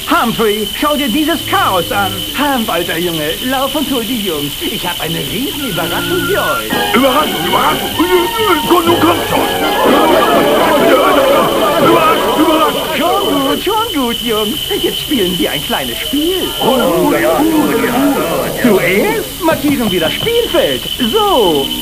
Ranger Woodlore (& Bär Humphrey)